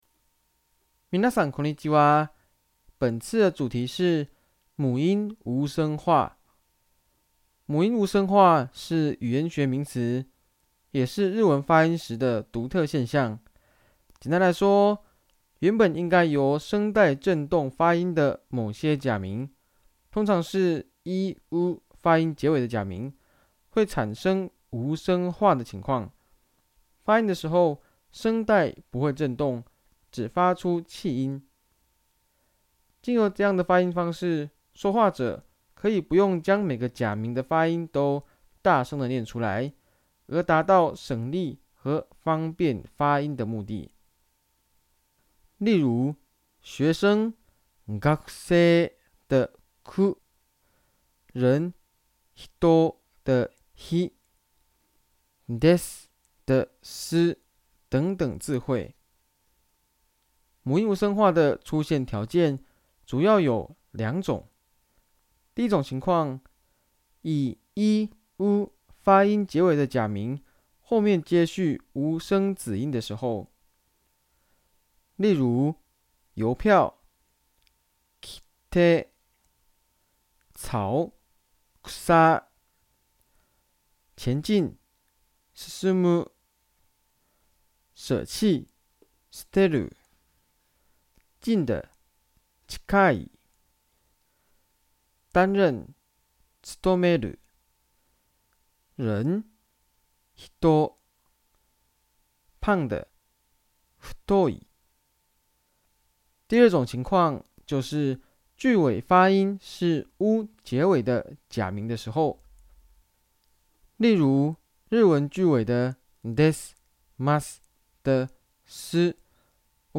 不發「SU」的音，而是發音成「S」呢?
聲音解說：
光靠文字並無法體會原始發音和母音無聲化的差異，請聆聽聲音檔，會更容易理解。
日文句尾出現的「です」「ます」，其中的假名「す」，我們習慣唸作「s」的發音，而不唸作「su」。